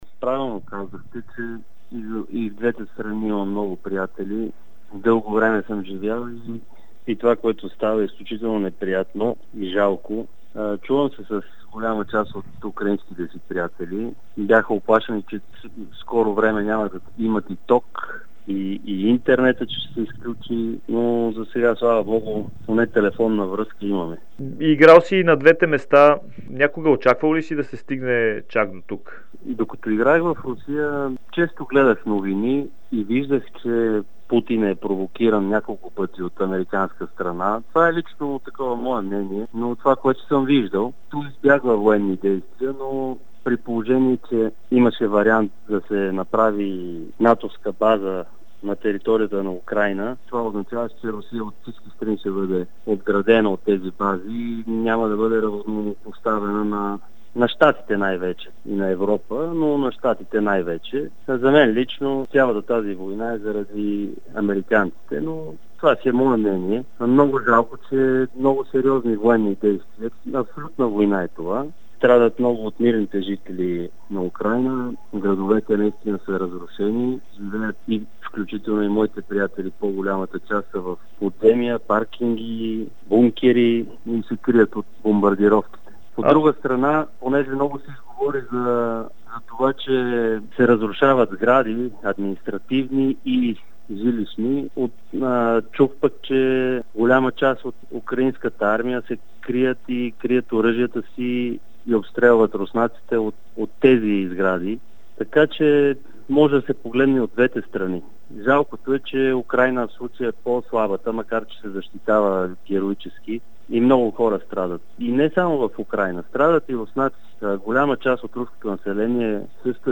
Бившият български национал Георги Пеев даде интервю за Дарик и dsport по темата за военните действия в Украйна. 42-годишният Пеев игра шест години в Украйна с екипите на Динамо Киев и Днипро преди да премине в Русия, където от 2007 до 2016 защитава цветовете на Амкар Перм.